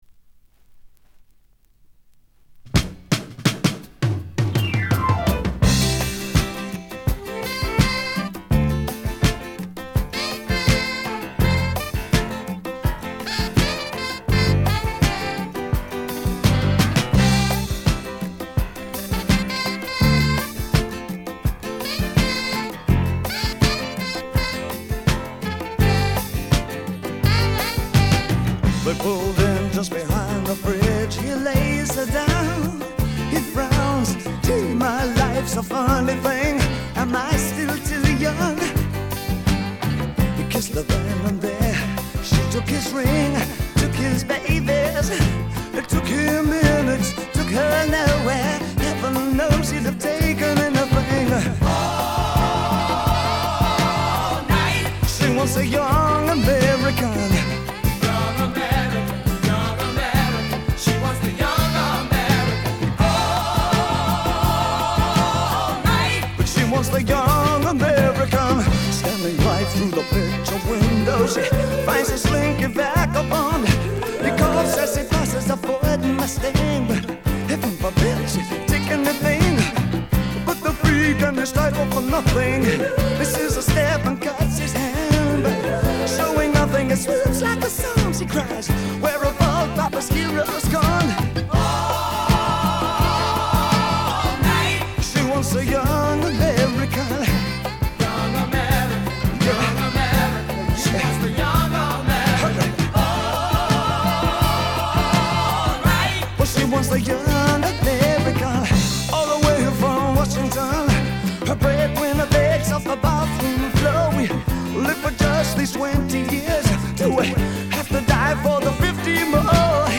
سبک سول و R&B